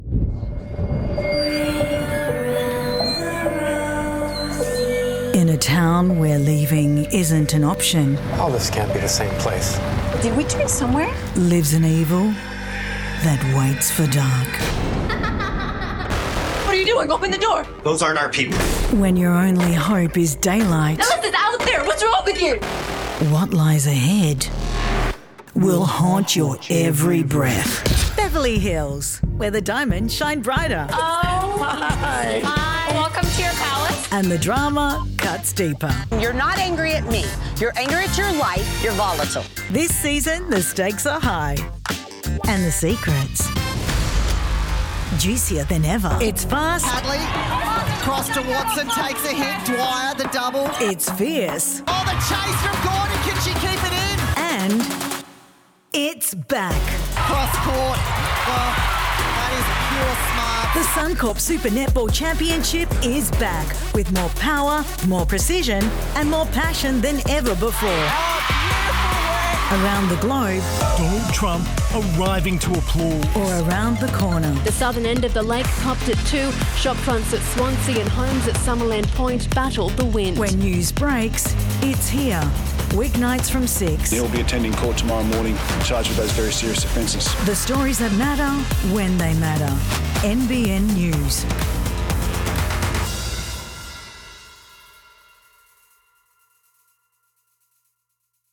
Female
English (Australian), English (Neutral - Mid Trans Atlantic)
Movie Trailers
Tv Promos